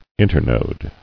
[in·ter·node]